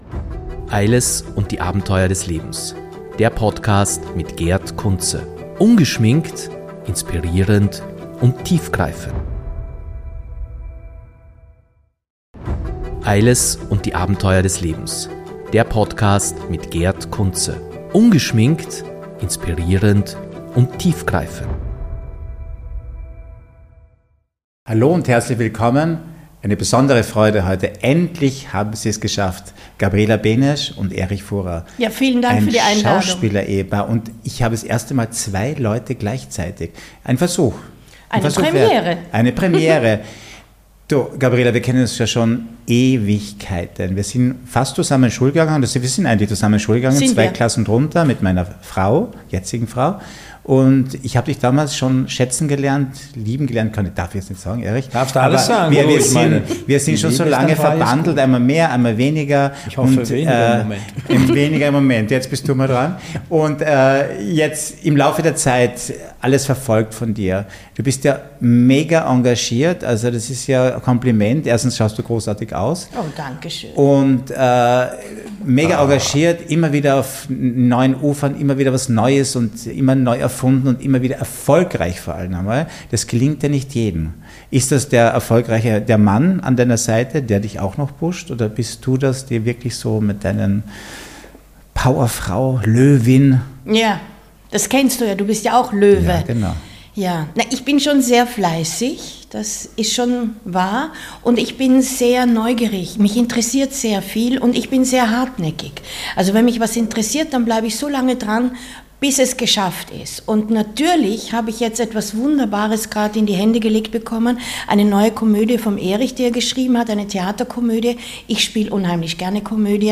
Beschreibung vor 1 Jahr Heute haben wir eine echte Premiere – zum ersten Mal sind gleich zwei Gäste im Eiles Podcast dabei
Die beiden sprechen über ihre Zusammenarbeit, aktuelle Projekte und natürlich auch über das Geheimnis ihrer langjährigen Verbindung – privat und beruflich. Von herzlichen Anekdoten bis hin zu spannenden Zukunftsplänen erwartet euch eine Folge voller Charme und Lachen.